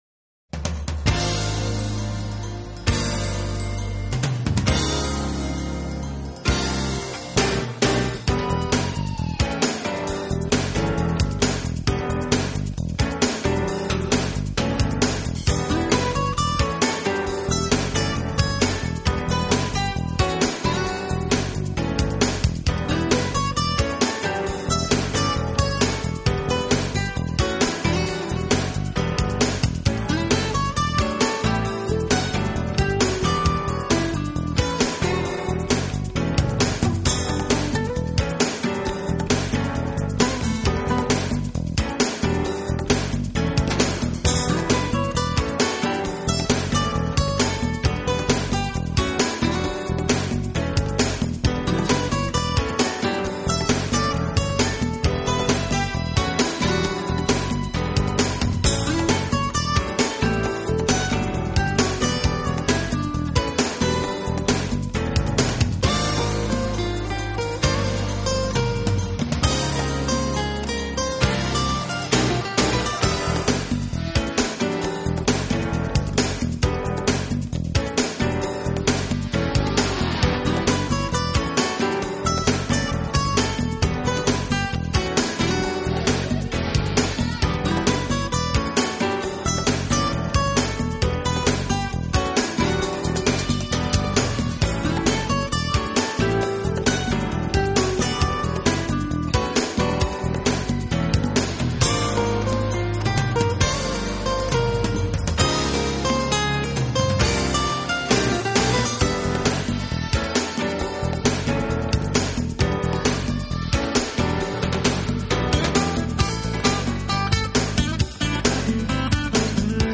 【爵士专辑】